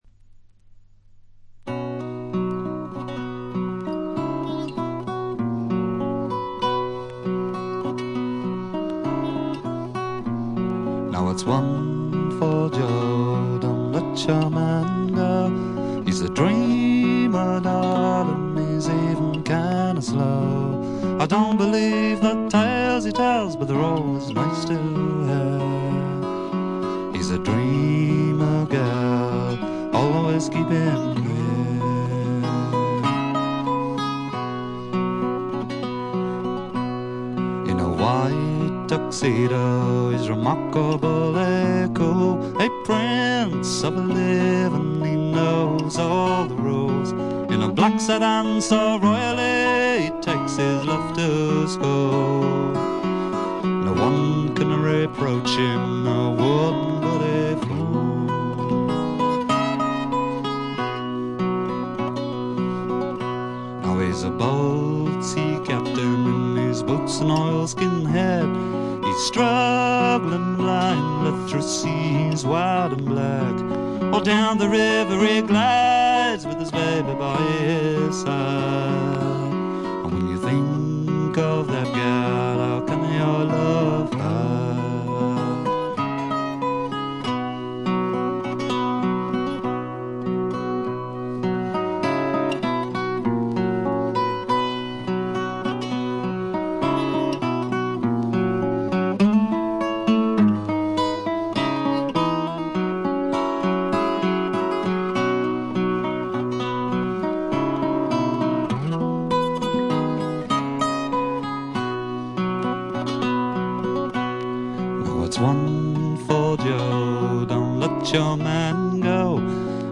チリプチがそこそこ。散発的なプツ音も少し。
試聴曲は現品からの取り込み音源です。